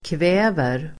Uttal: [kv'ä:ver]